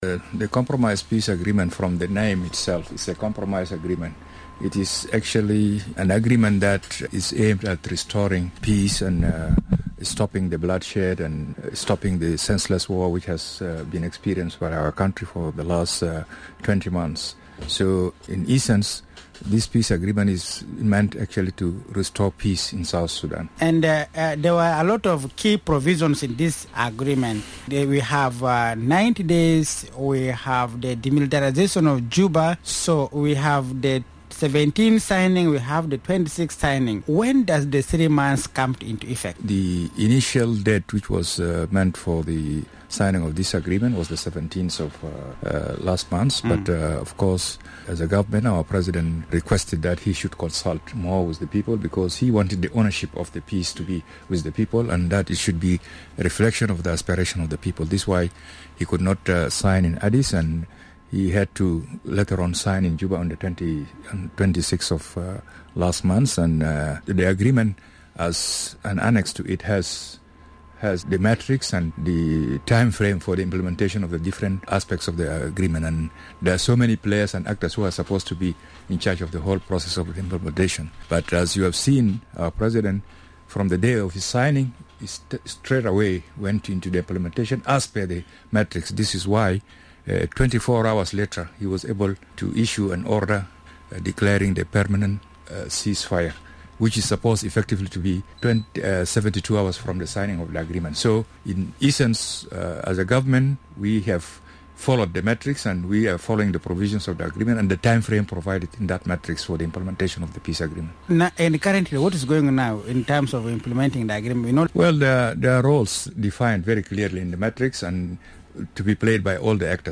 Speaking on Radio Miraya this morning, Peter Bashir Gbandi said the IGAD workshop was crucial to help the parties understand the procedures of implementation.